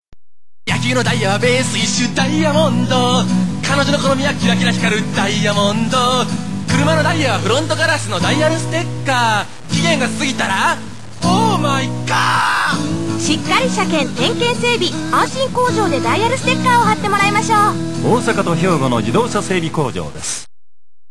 (ちょっとシャウト系の感じで歌う)〜
ストリート　ミュージシャン